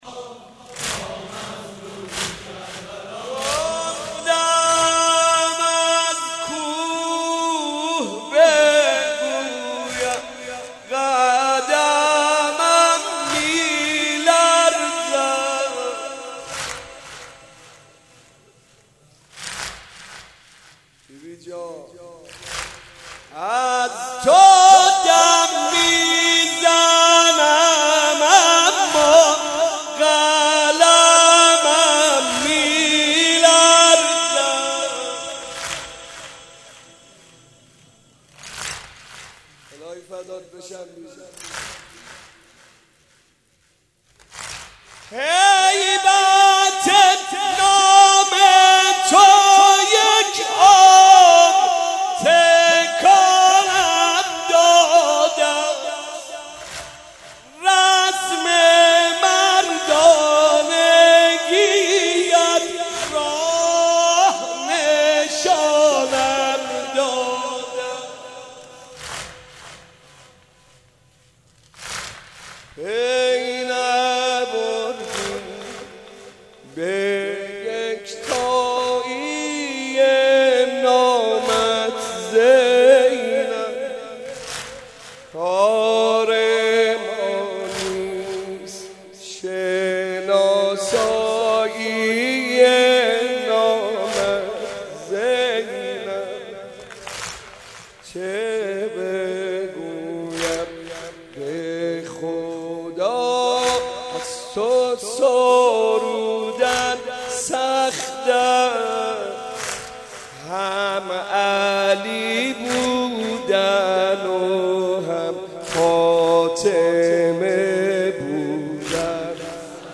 شب شهادت حضرت زینب(س)/مکتب الزهرا(س)